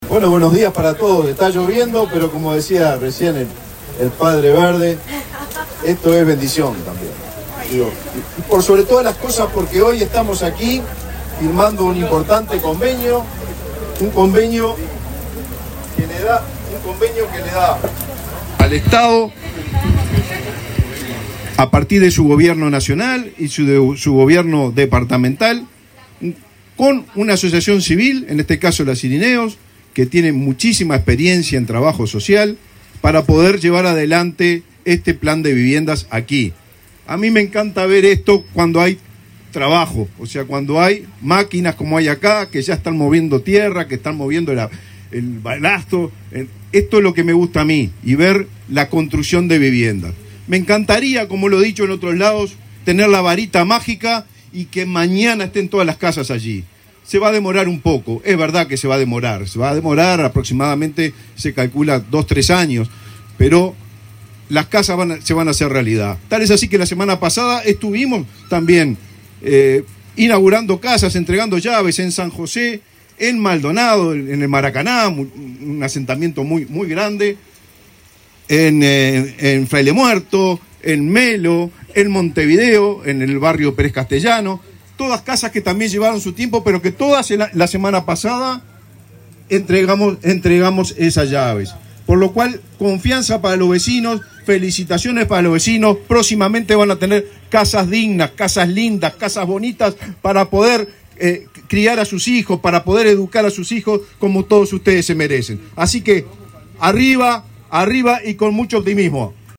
Palabras del ministro de Vivienda, Raúl Lozano